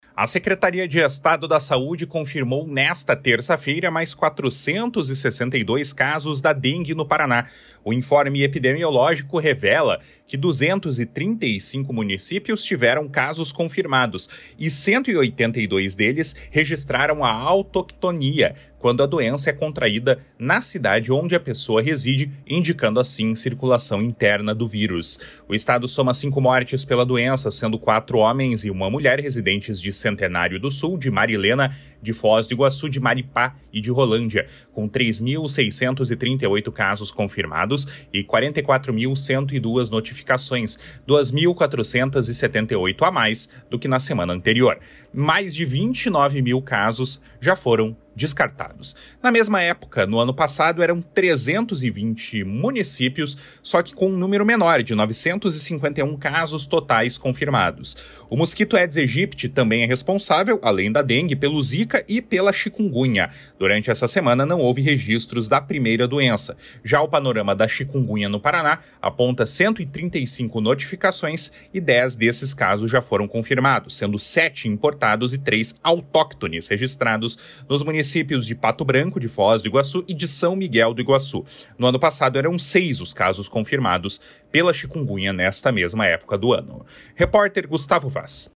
Boletim semanal da dengue registra 462 novos casos da doença